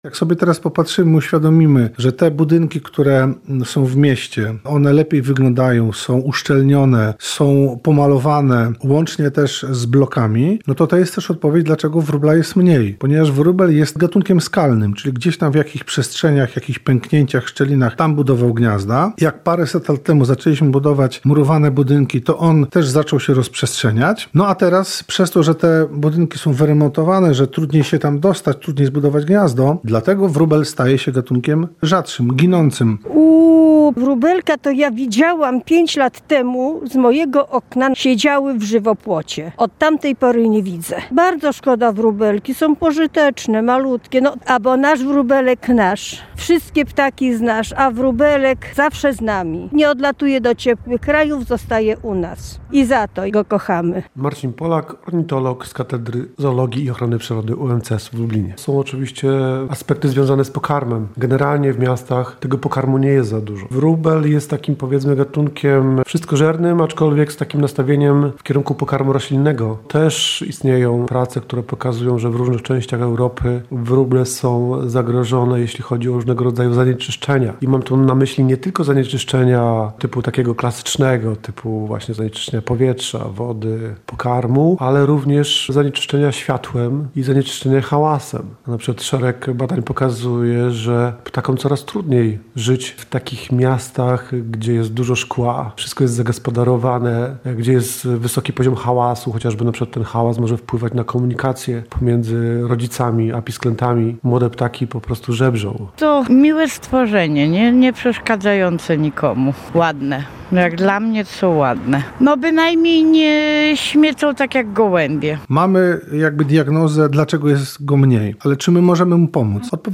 W mieście powstanie mural Pytani przez naszą reporterkę mieszkańcy Lublina przyznają, że dawno nie spotakali tych ptaków.